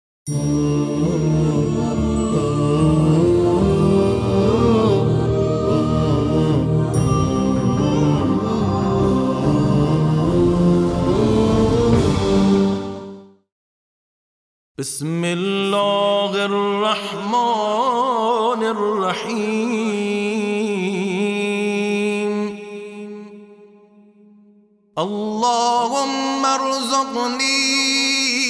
د روژې د مبارکې میاشتې د ۱۵ ورځې په زړه پورې لنډه دعا
نو راځئ چې د روژې د مبارکې میاشتې د پڼځلسمې  د  ورځې دعا په ګډه سره زمزمه کړو ؛